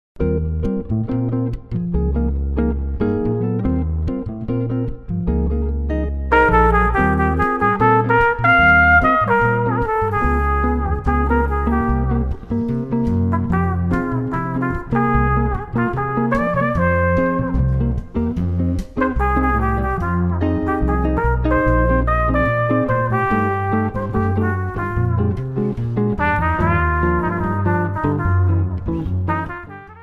Manhattan Jazz Trio plays cool sophisticated jazz.
warm trumpet
double bass
This trio line-up is excellent for unobtrusive background jazz during cocktails, wedding receptions, dinner jazz and, dancing.